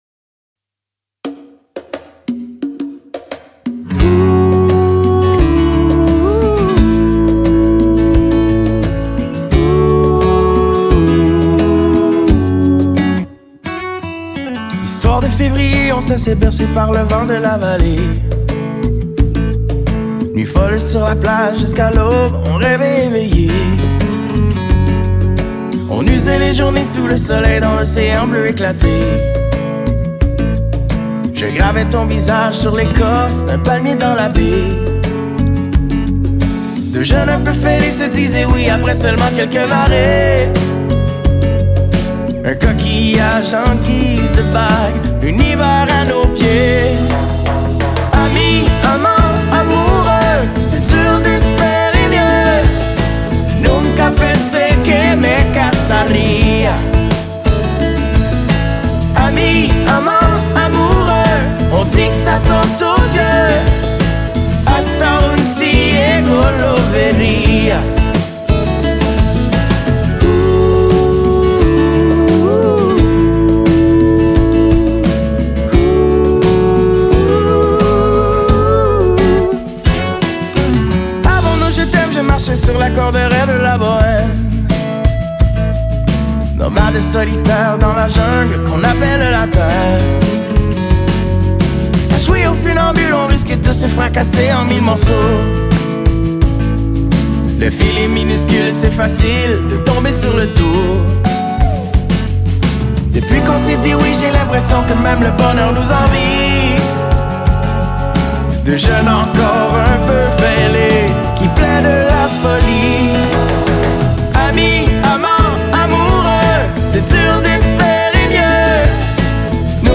«flamenpop»